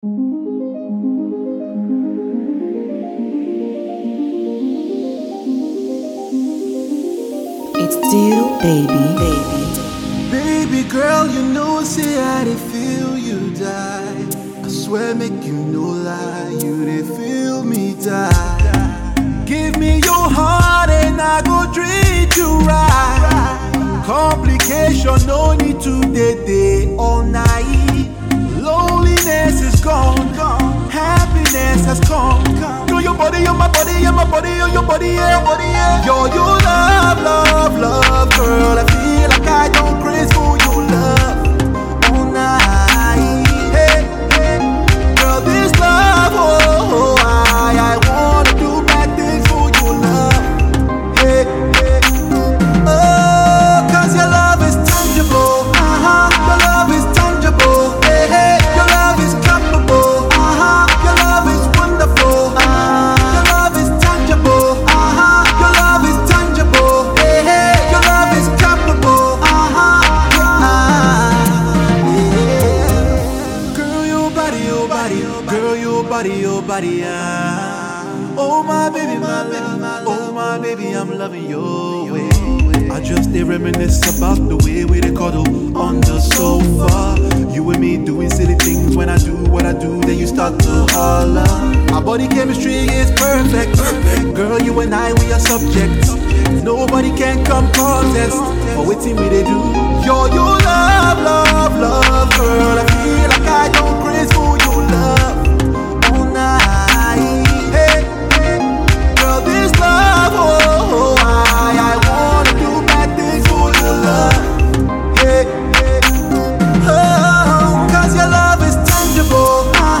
upbeat Afro-RnB love song